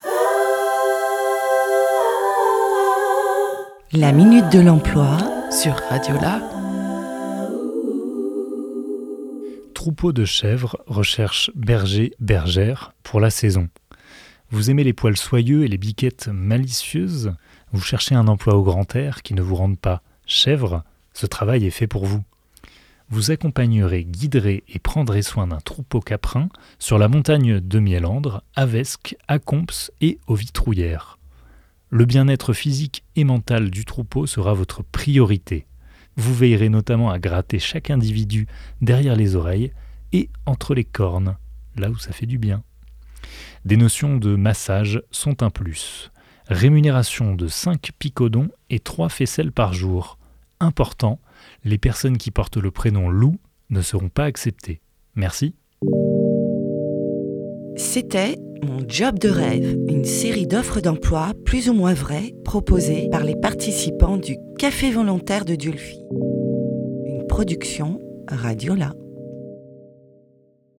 « Mon job de rêve » est une série d’offres d’emploi décalées, écrite et enregistrée par des participant-es du Café volontaire de Dieulefit en juillet 2024.